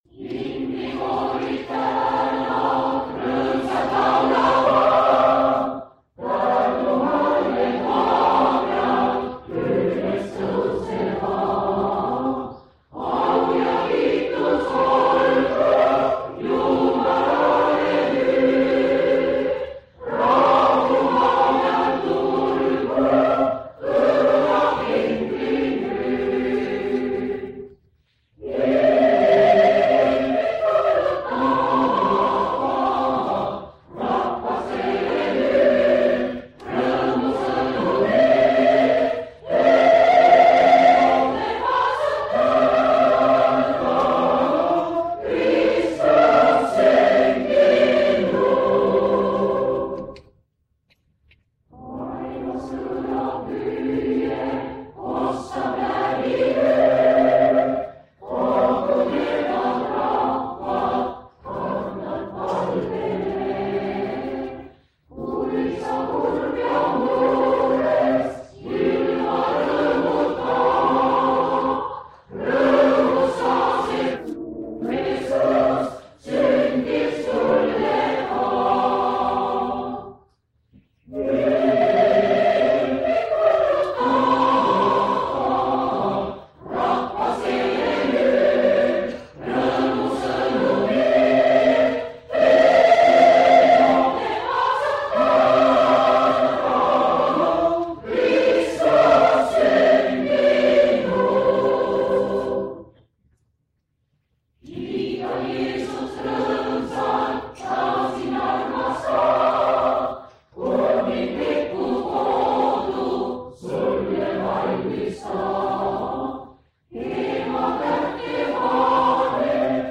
Instrum.pala orelil Tallinna segakoor - Helisegu rõõmukellad Jõuluevangeelium 1 Tallinna kandled - Instrum.
Jõuluteenistus 1977 Tallinnas (Tallinnas)
Koosolekute helisalvestused
KLAVERI KELLAD